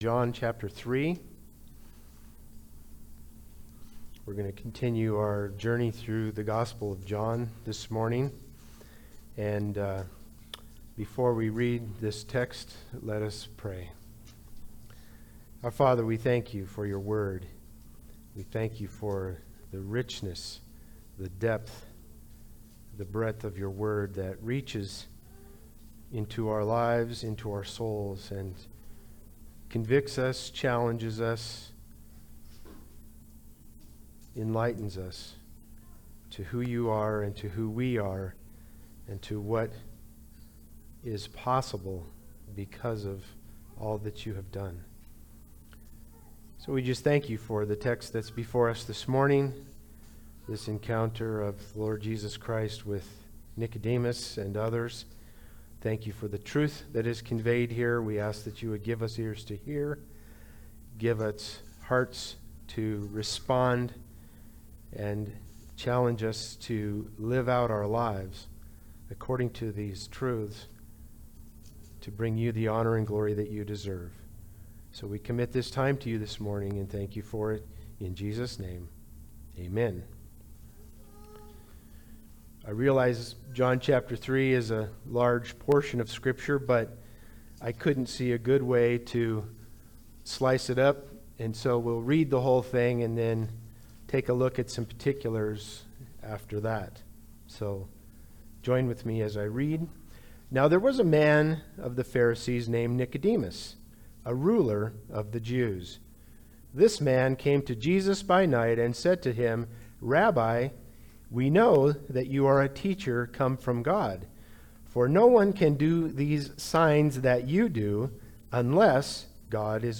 Passage: John 3 Service Type: Sunday Service